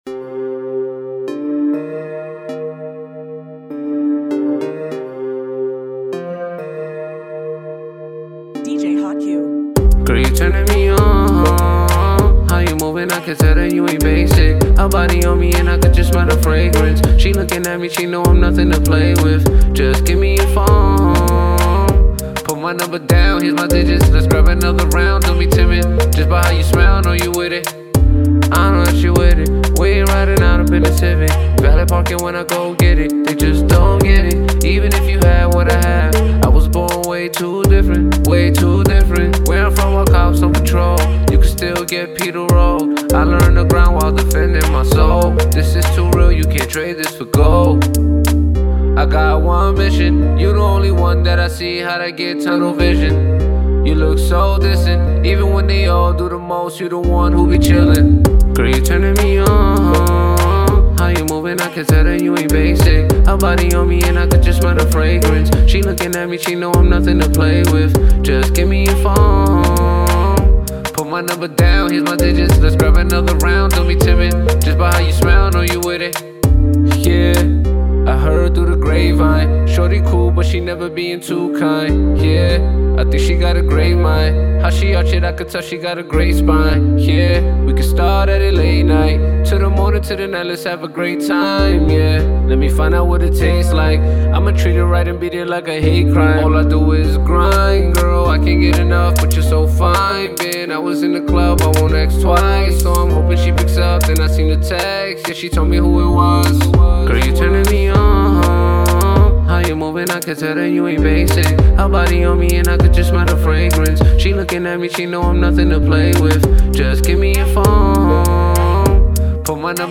A dope Caribbean vibe track to start your summer of right!